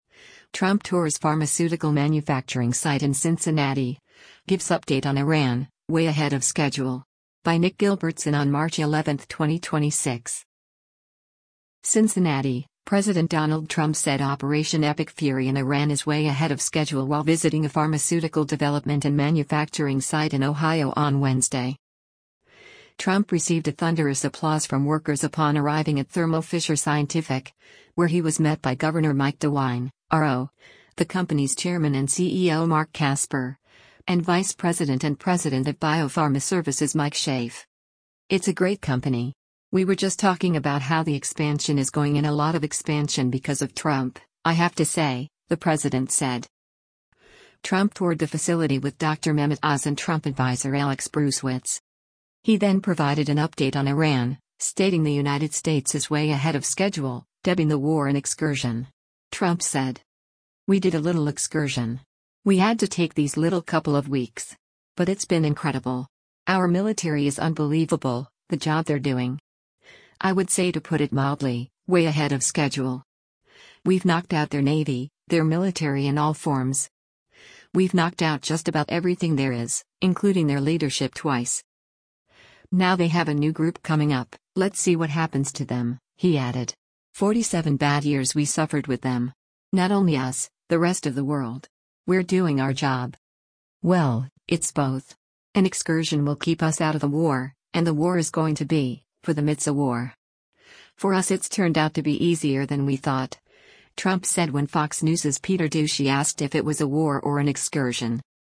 Trump Tours Pharmaceutical Manufacturing Site in Cincinnati, Gives Update on Iran: ‘Way Ahead of Schedule’
CINCINNATI—President Donald Trump said Operation Epic Fury in Iran is “way ahead of schedule” while visiting a pharmaceutical development and manufacturing site in Ohio on Wednesday.